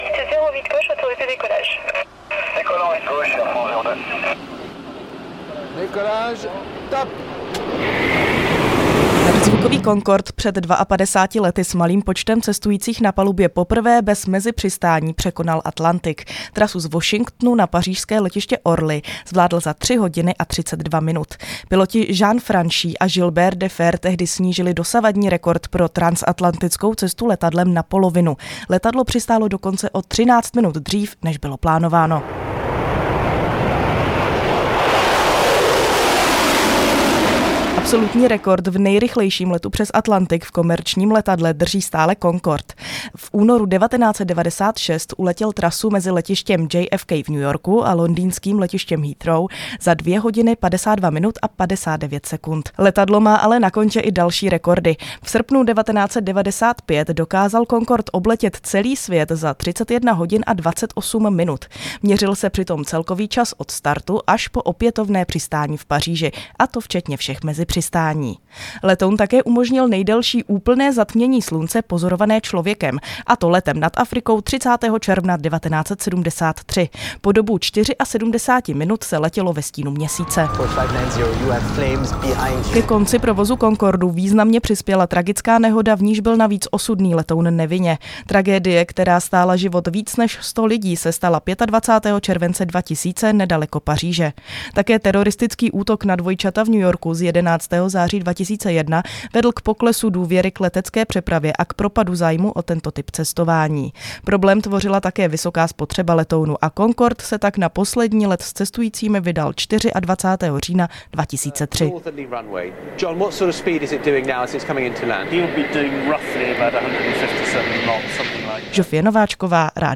REPORTÁŽ
Reportáž o prvním transatlantickém letu Concordu